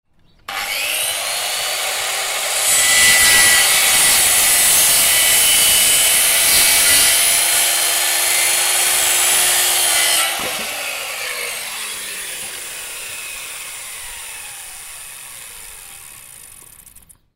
Machinery Sound Effects
Construction
Skilsaw_1.mp3